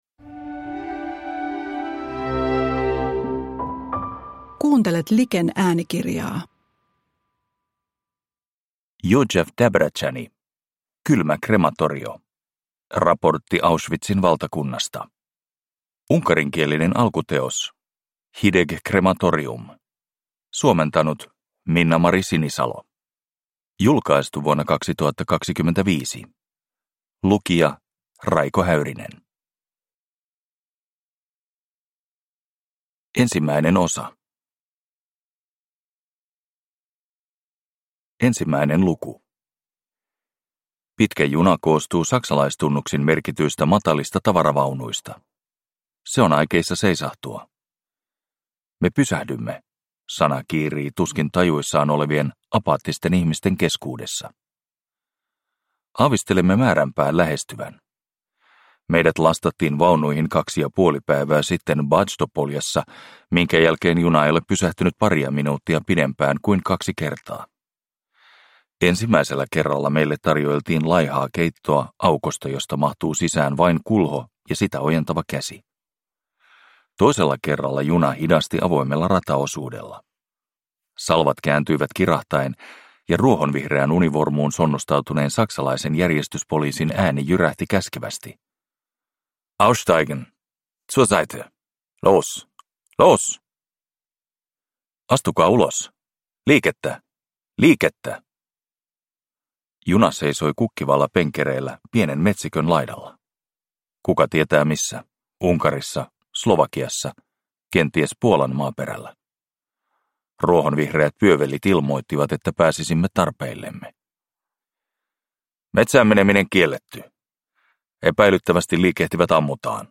Kylmä krematorio – Ljudbok